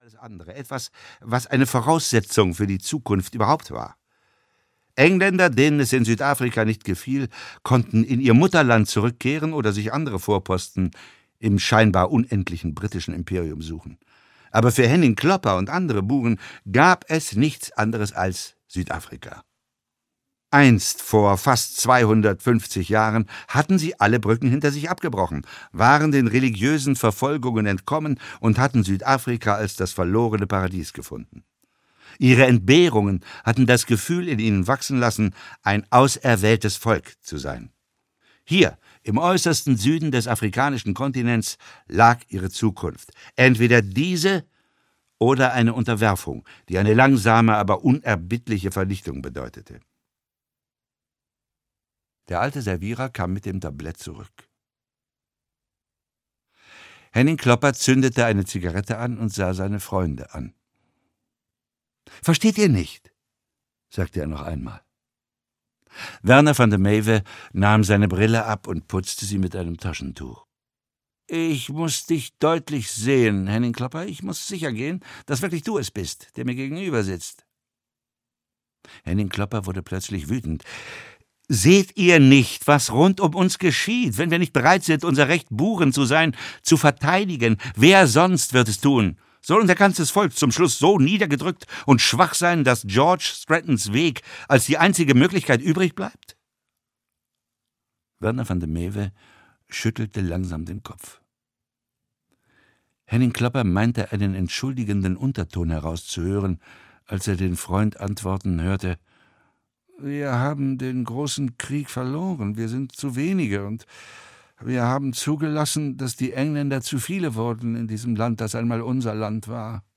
Hörbuch Die weiße Löwin (Ein Kurt-Wallander-Krimi 4), Henning Mankell.